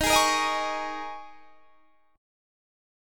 Listen to D#7sus4 strummed